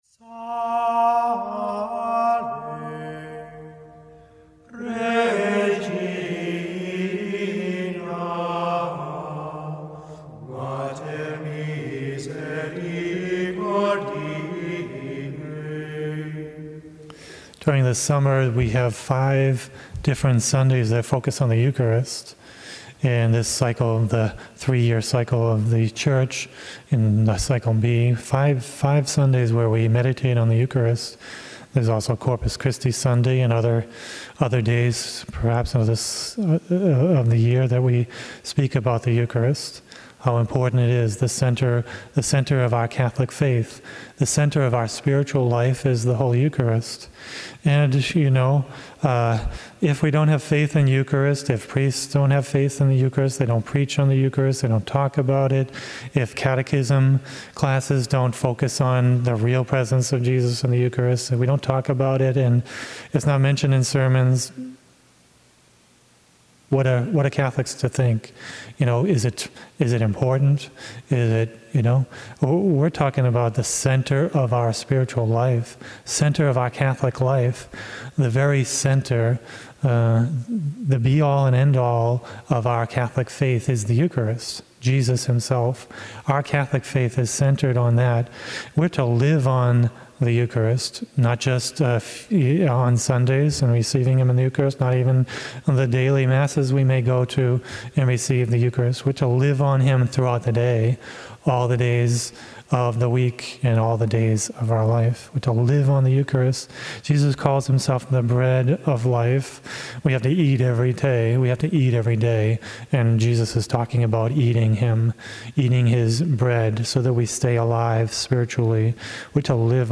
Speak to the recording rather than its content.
Mass: 19th Sunday in Ordinary Time - Sunday - Form: OF Readings: 1st: 1ki 19:4-8 Resp: psa 34:2-3, 4-5, 6-7, 8-9 2nd: eph 4:30-5:2 Gsp: joh 6:41-51